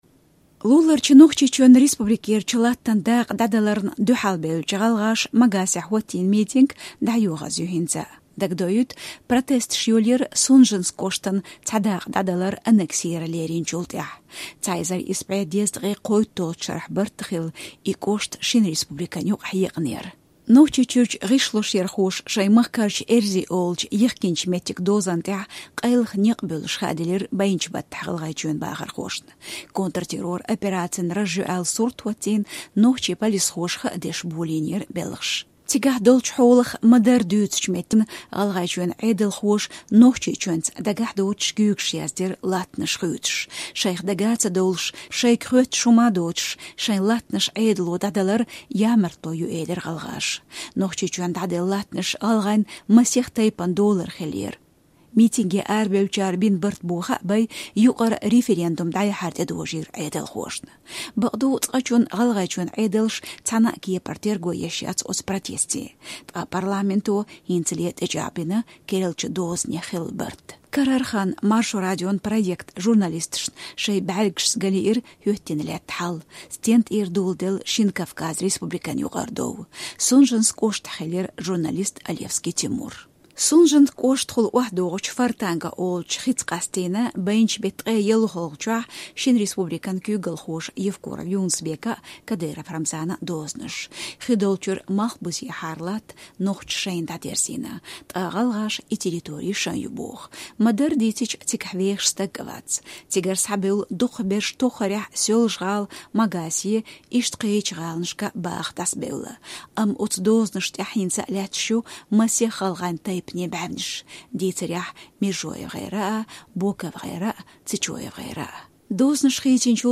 Нохчийчоьно а, ГIалгIайчоьно а къуьйсучу дозанех репортаж